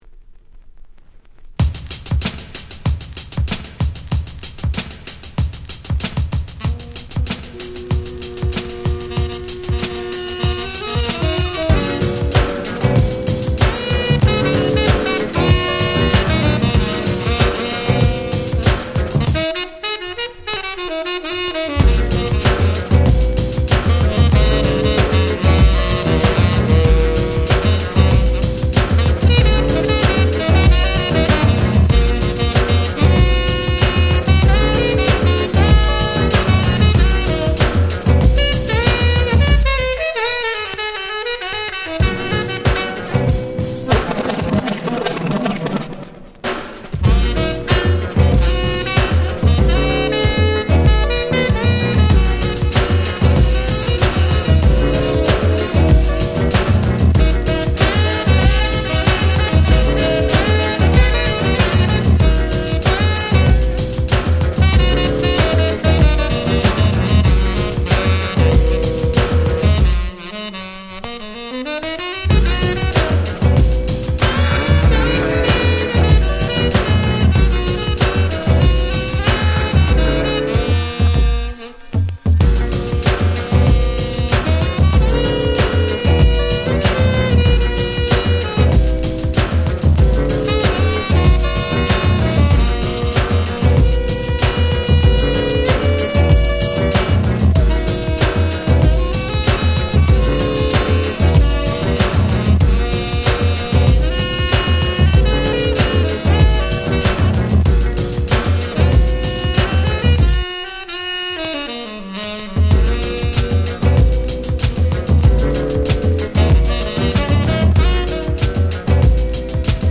92' Hip Hop Super Classics !!
90's Boom Bap ブーンバップ